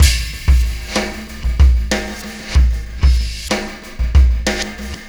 Pulsar Beat 19.wav